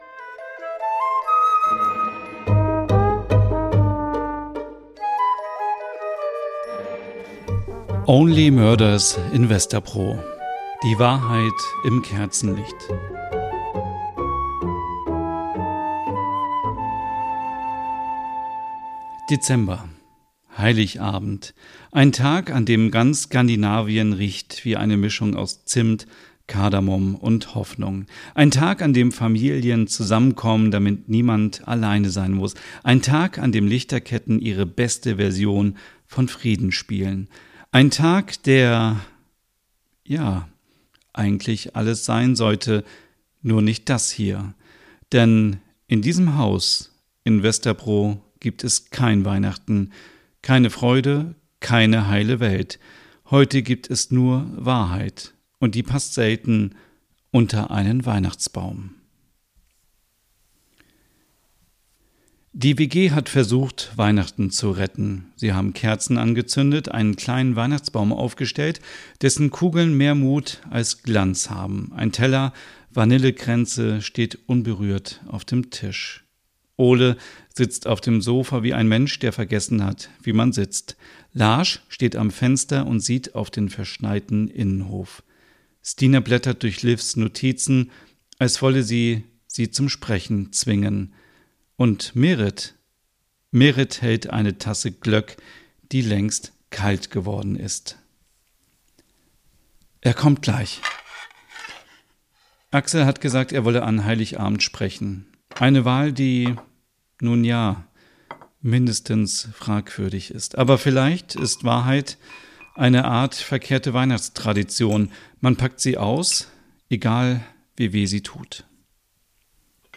Dann entsteht Only Murders in Vesterbro: ein weihnachtliches Crime-Hörspiel voller nordischer Atmosphäre, schräger Charaktere und warmem Erzähler-Ton.